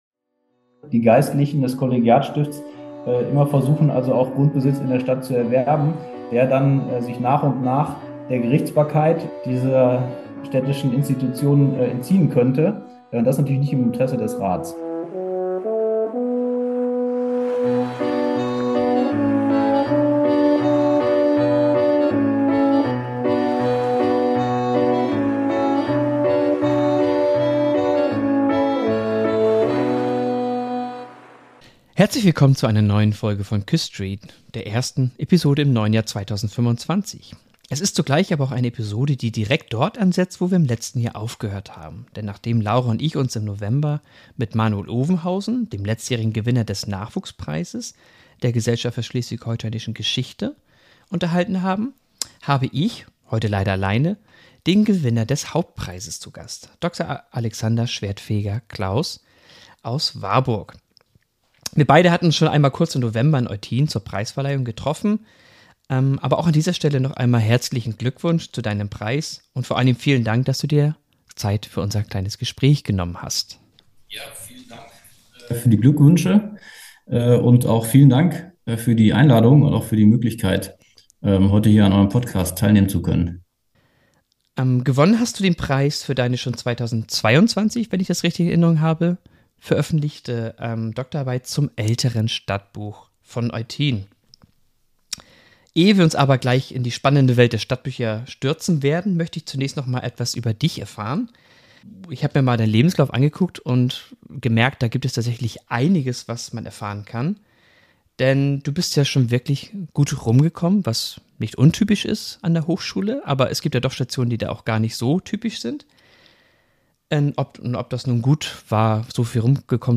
Datum des Gesprächs: 8. Januar 2025 (digital)